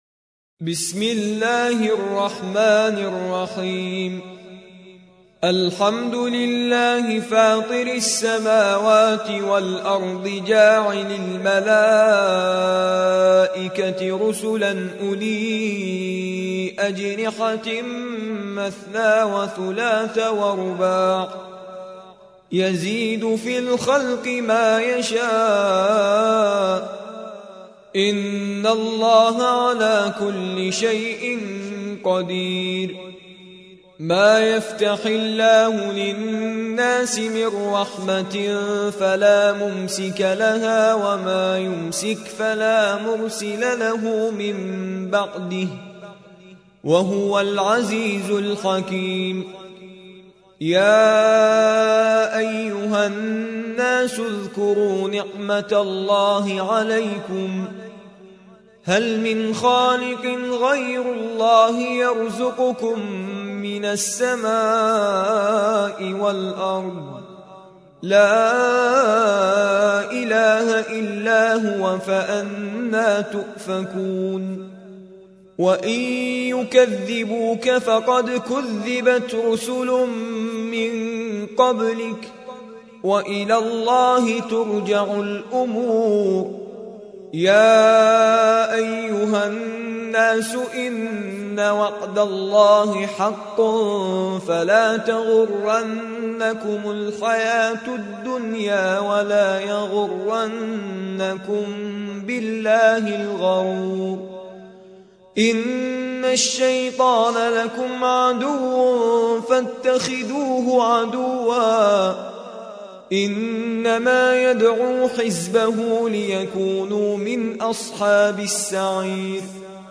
35. سورة فاطر / القارئ